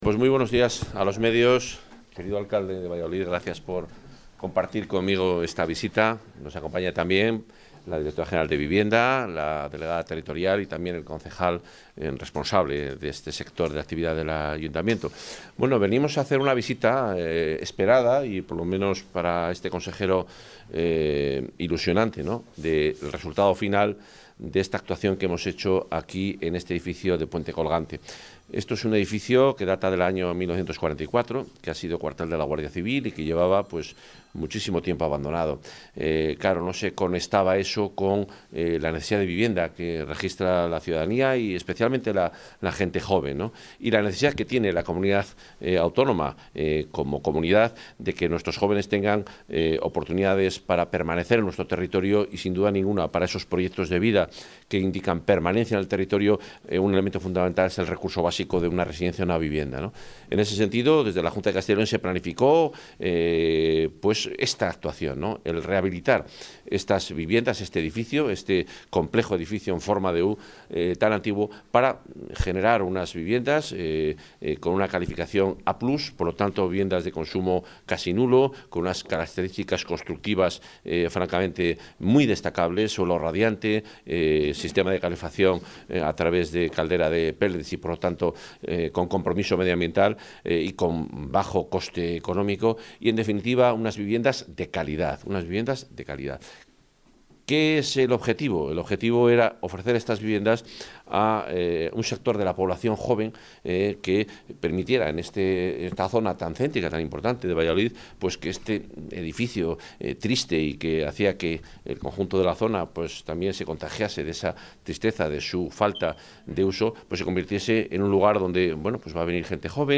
Reunión anual del Clúster de Automoción y Movilidad de Castilla y León (FACYL)
Intervención del consejero (1.958 kbytes).